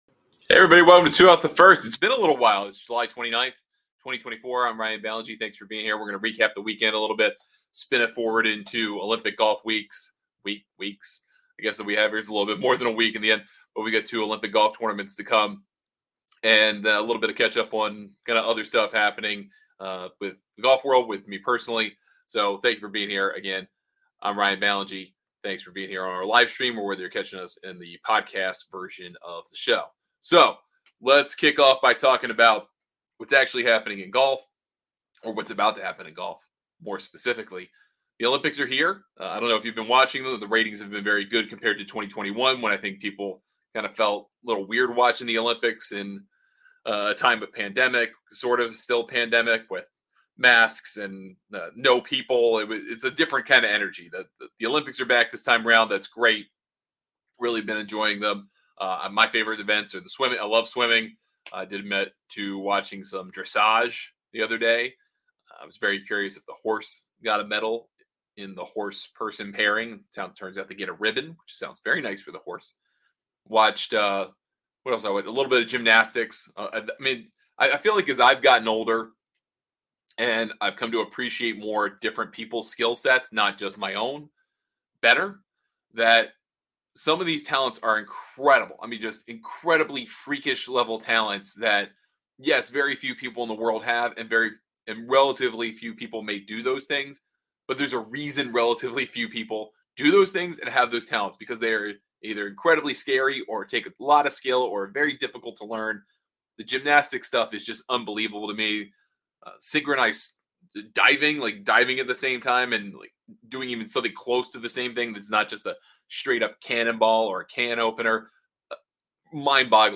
On today's LIVE show